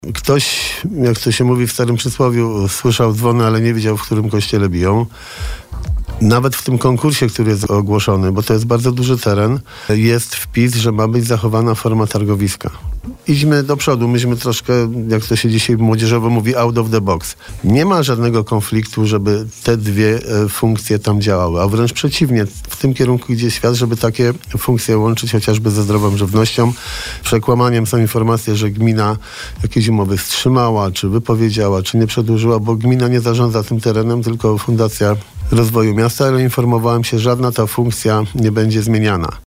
– Tak dziś, jak i w przyszłości, gmina planuje, żeby targowisko tam było – mówił na naszej antenie prezydent Bielska-Białej. Jarosław Klimaszewski pytany przez nas o dalsze losy targowiska niedaleko stacji kolejowej w Lipniku, odpowiedział, że w ogłoszonym konkursie na koncepcję urbanistyczno-architektoniczną zawarto wpis dotyczący zachowania funkcji targowiska w tym miejscu.